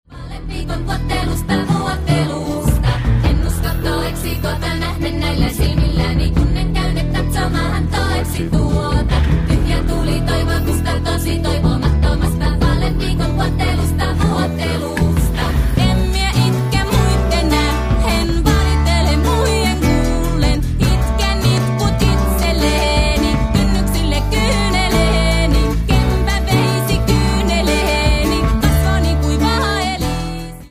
A sad song